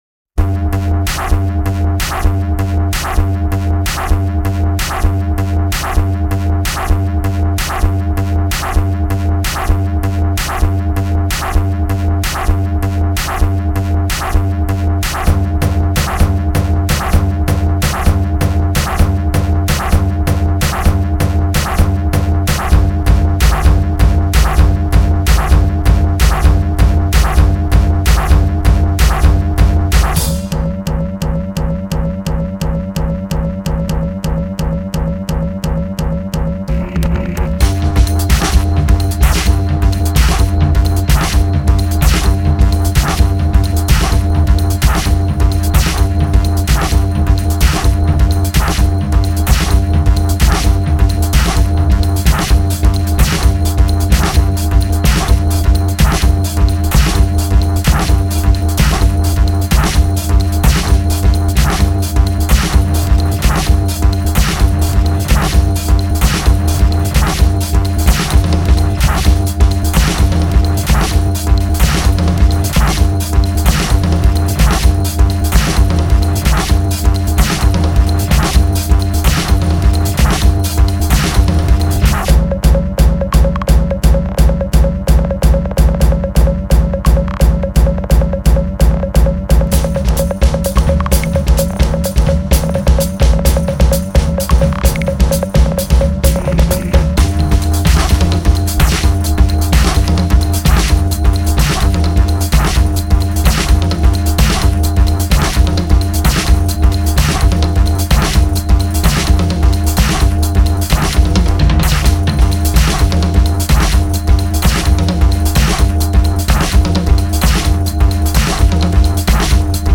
mastering his acoustic drumset